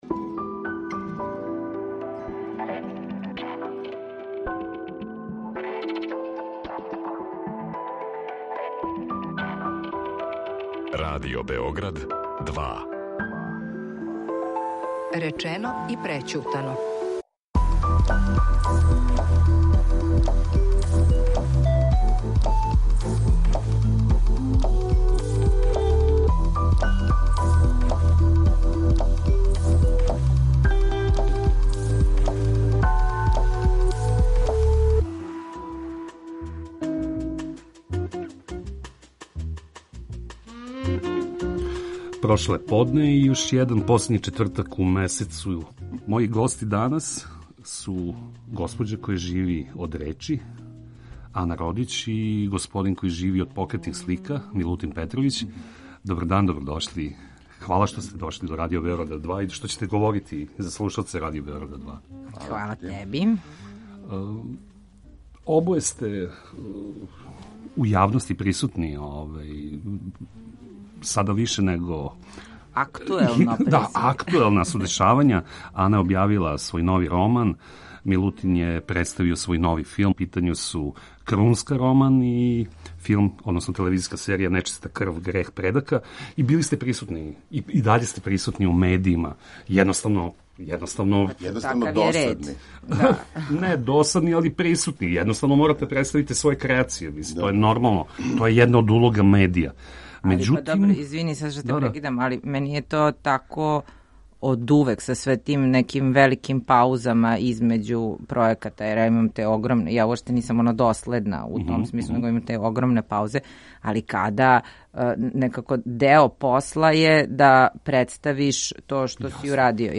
Гости емисије данас говоре о притиску медија на приватност јавних личности.